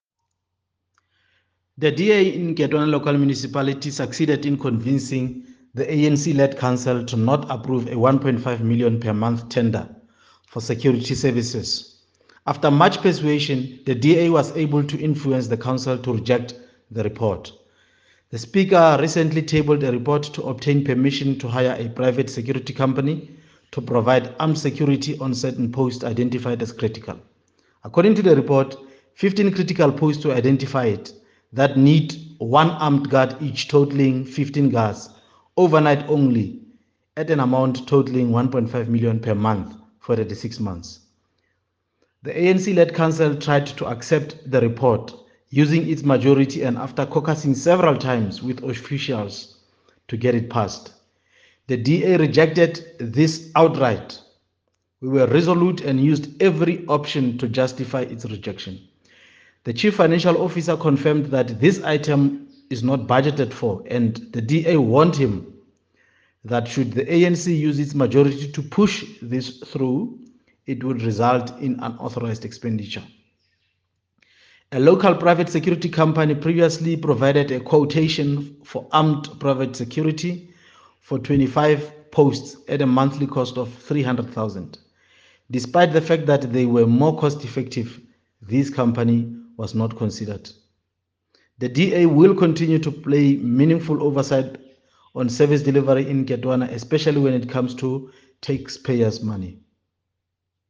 Issued by Diphapang Mofokeng – DA Councillor in Nketoana Local Municipality
Sotho soundbites by Cllr Diphapang Mofokeng.